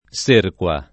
serqua [ S% rk U a ] s. f.